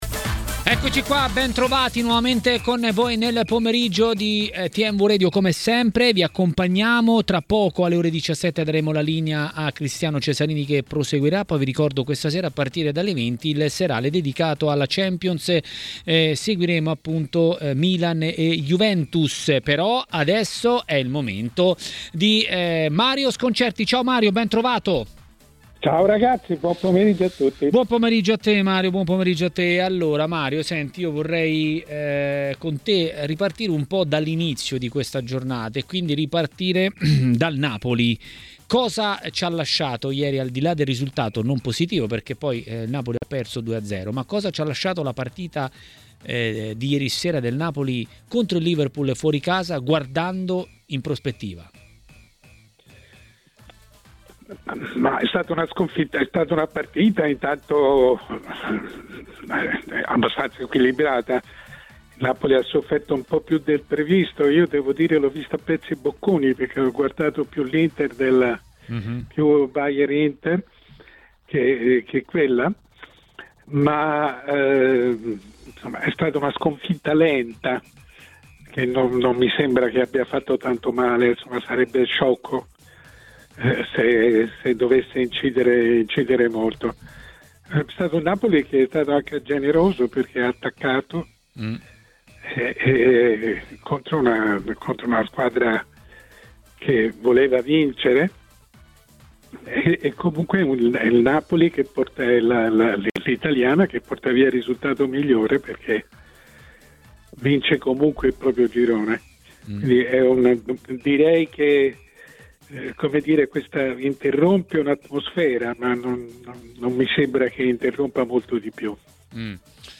A Maracanà, nel pomeriggio di TMW Radio, è intervenuto il direttore Mario Sconcerti.